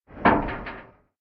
ambienturban_4.ogg